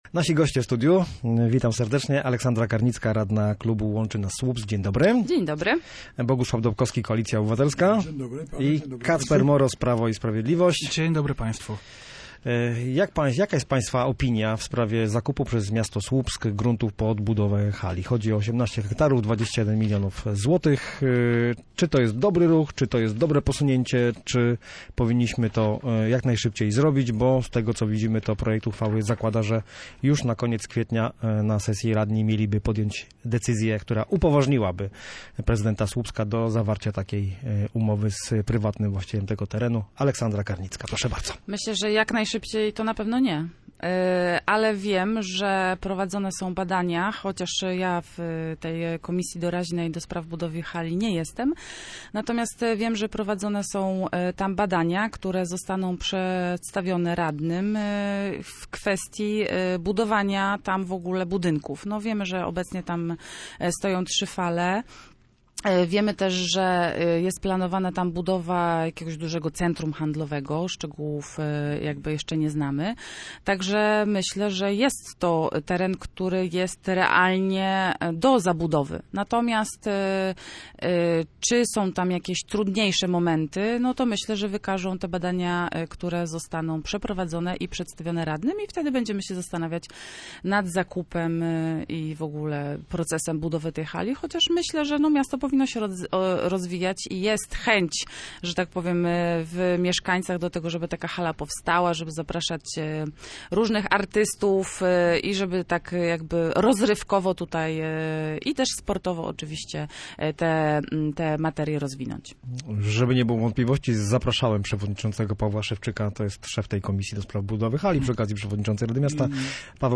Słupscy radni byli gośćmi miejskiego programu Radia Gdańsk „Studio Słupsk 102 FM”.